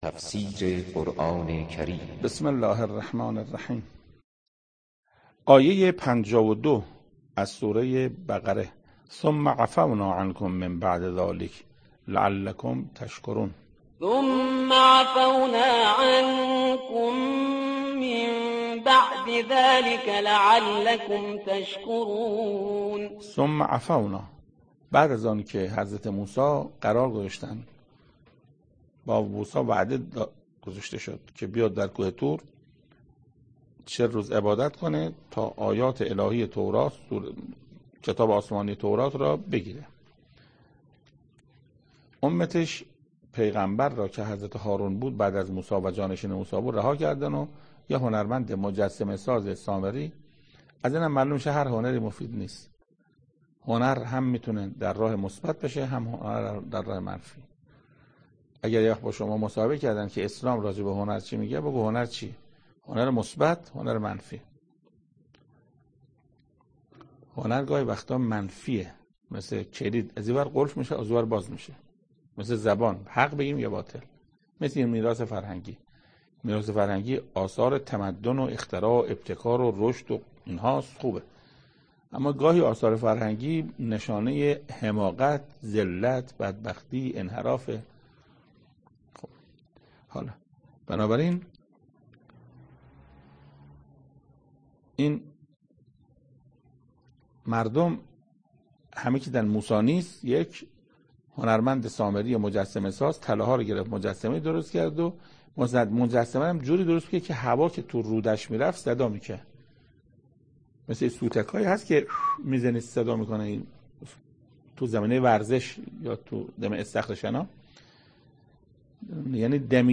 تفسیر آیه 52 و 53 سوره بقره - استاد محسن قرائتی در این بخش از ضیاءالصالحین، صوت تفسیر آیه پنجاه و دوم و پنجاه و سوم سوره مبارکه بقره را در کلام حجت الاسلام استاد محسن قرائتی به مدت 6 دقیقه با شما قرآن دوستان عزیز به اشتراک می گذاریم.